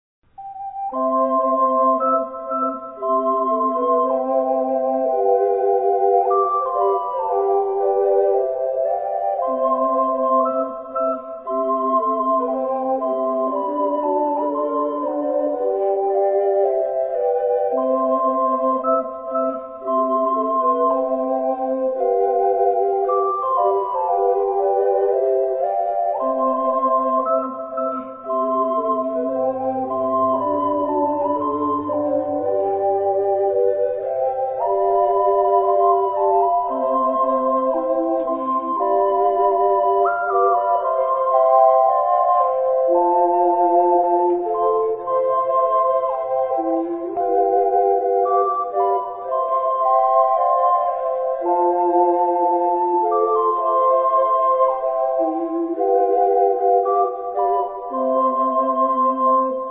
Franz Schubert: Klavier-Quintett op.114 Die Forelle　(for 4 Ocarina)
for 4ocarinas （ in F in C Big F Bass C ）
Ocarina
(Test recording)